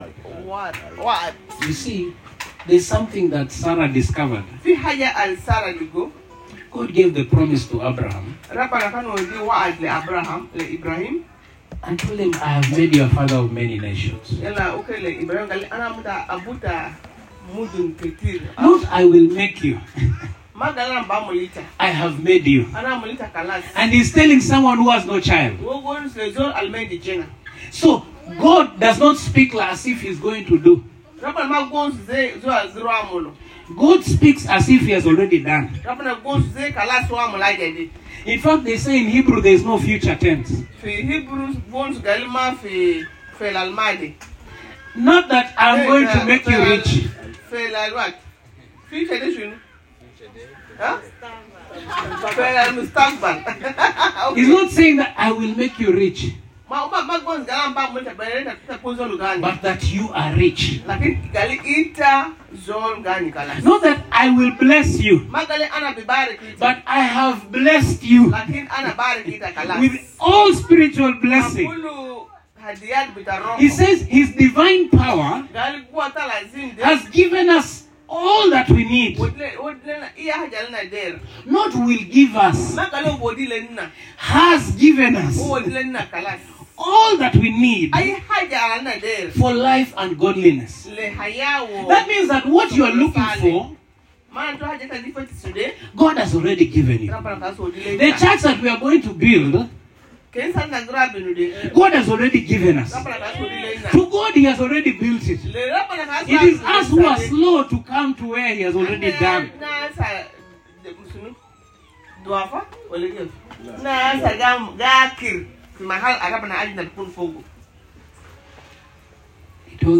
The Persecuted Church - Agape Sermon - Spirit and Life Ministries